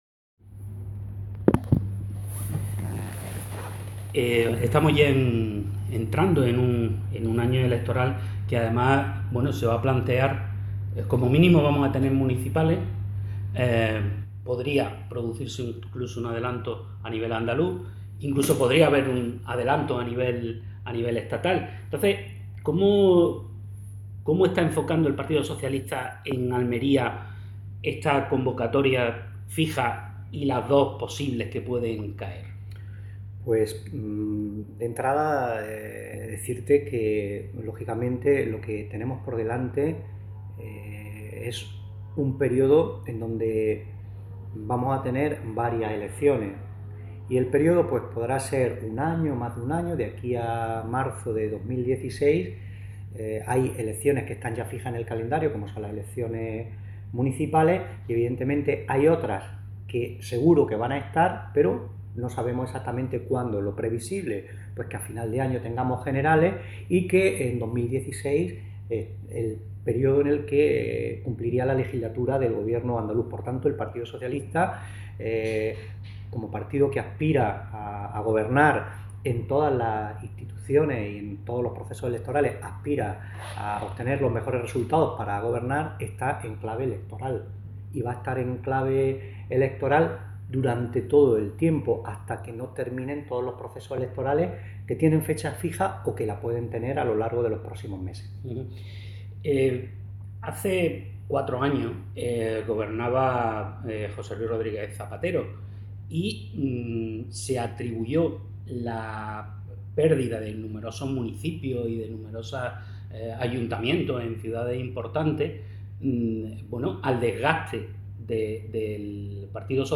Escuche la entrevista José Luis Sánchez Teruel no es de los que se precipitan.